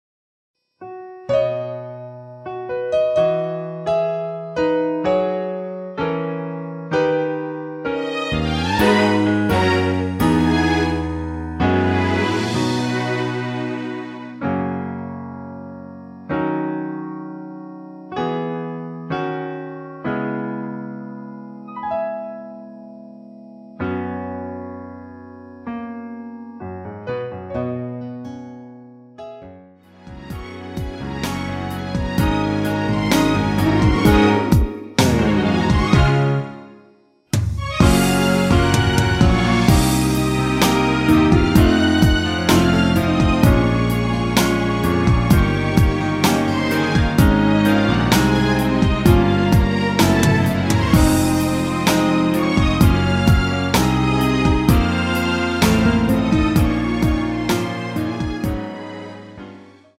축가에 잘 어울리는 곡 입니다.
원곡의 보컬 목소리를 MR에 약하게 넣어서 제작한 MR이며